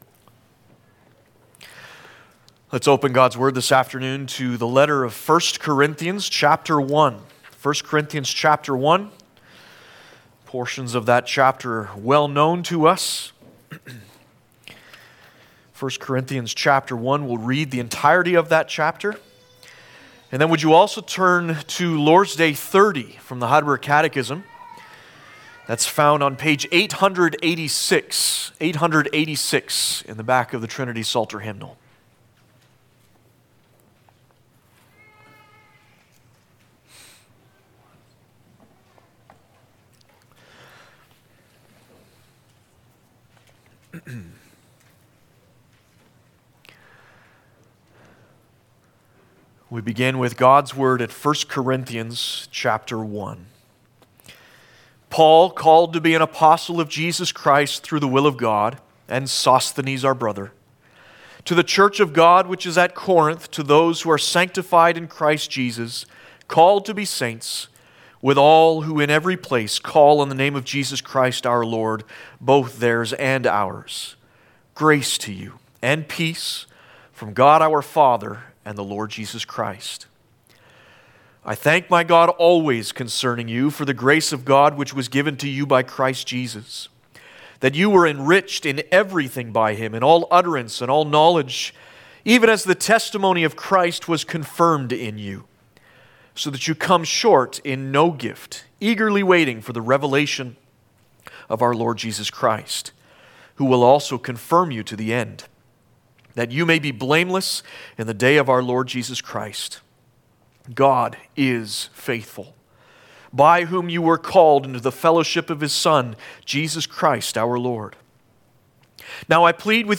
Heidelberg Catechism Passage: 1 Corinthians 1 Service Type: Sunday Afternoon Lord’s Day 30 « Do not neglect so great a salvation!